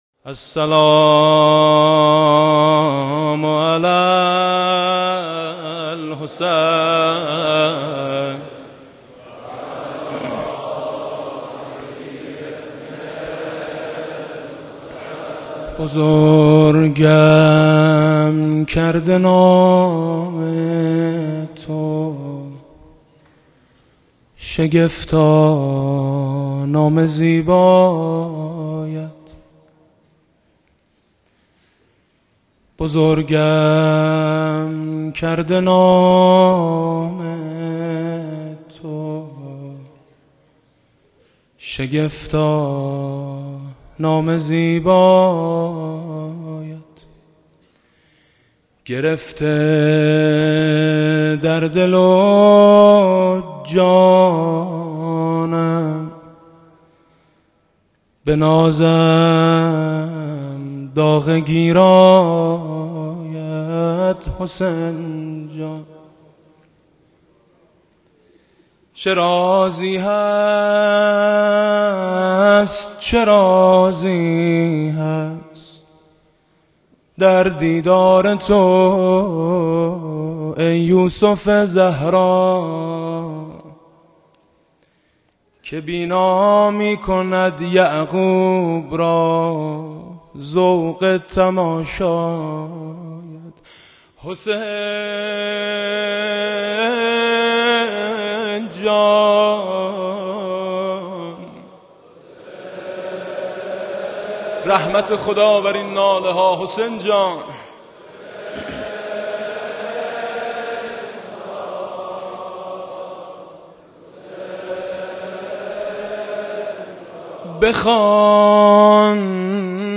روضه | بزرگم کرده نام تو شگفتا نام زیبایت
روضه خوانی حاج میثم مطیعی در هفتمین شب محرم سال 1391 | محضر رهبر انقلاب اسلامی | حسینیه امام خمینی(ره)